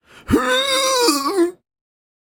get-sick.ogg